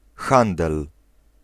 Ääntäminen
Synonyymit échange négoce Ääntäminen France: IPA: [kɔ.mɛʁs] Haettu sana löytyi näillä lähdekielillä: ranska Käännös Ääninäyte Substantiivit 1. handel {m} Suku: m .